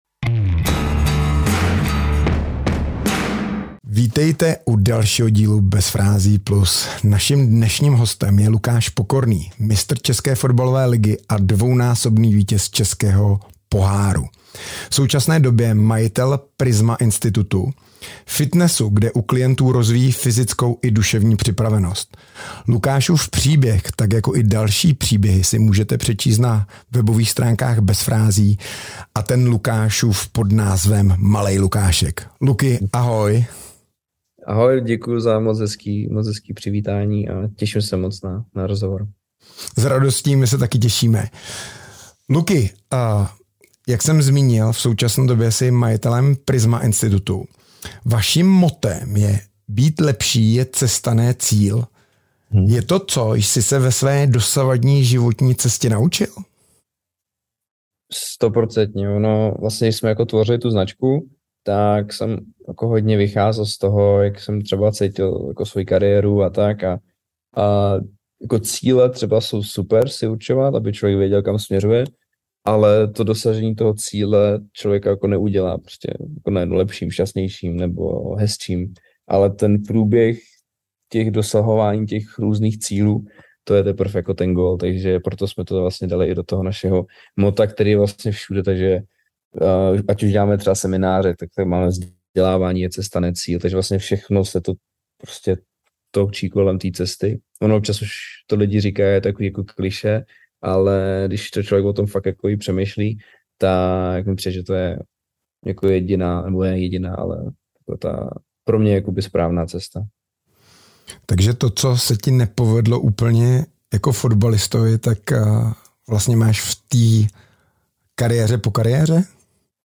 Hostem dalšího dílu Bez frází+ je bývalý fotbalista Lukáš Pokorný .